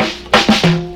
Slide Fill.wav